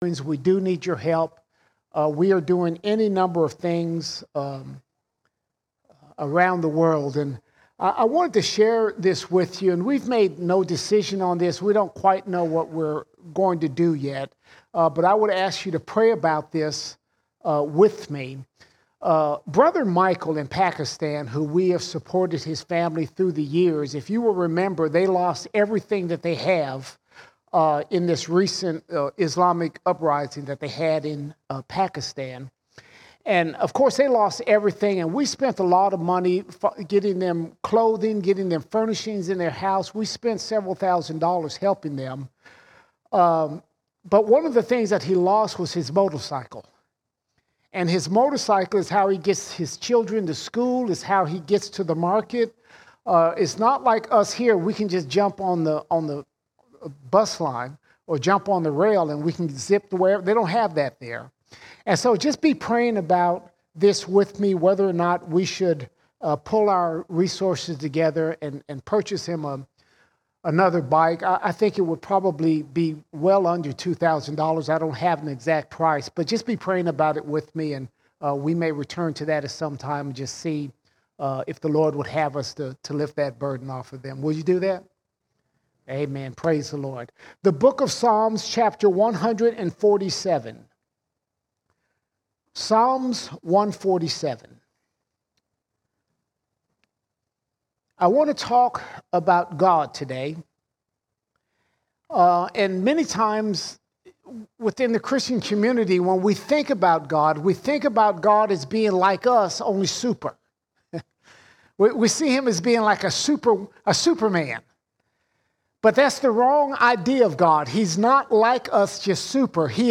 11 December 2023 Series: Sunday Sermons Topic: spiritual growth All Sermons Three Questions Three Questions Here are three probing questions you need to ask about your relationship with God.